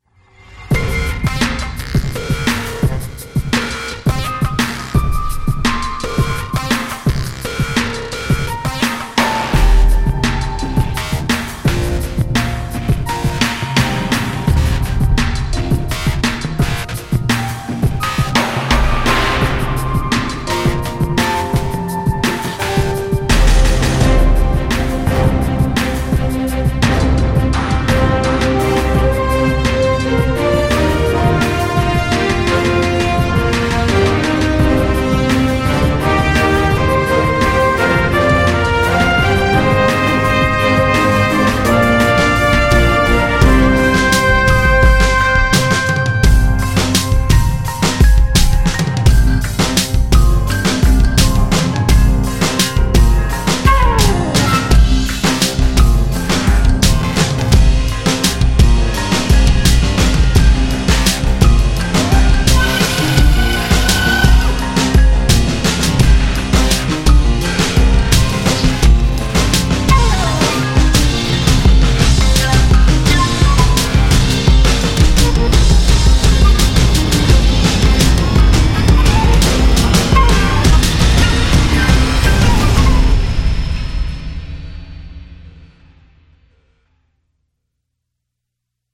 描述：古典乐|欢快
Tag: 钢琴 弦乐器 Horn